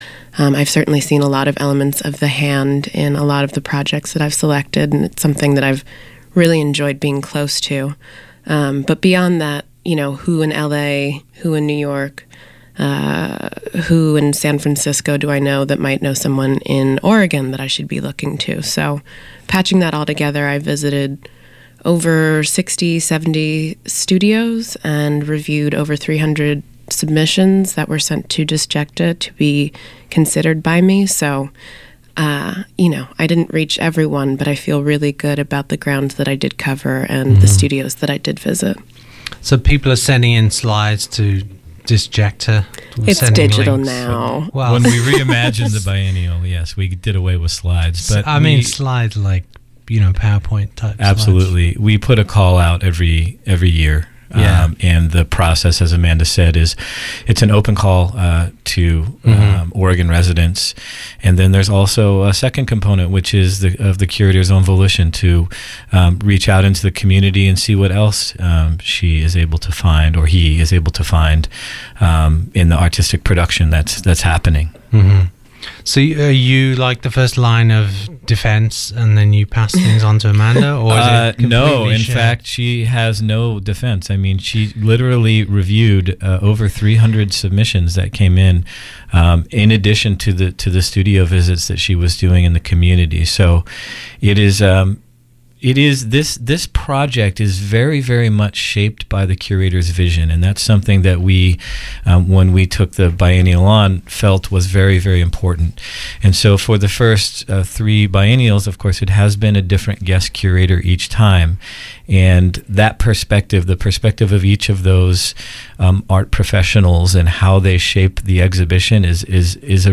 hosts a discussion between the architectural writer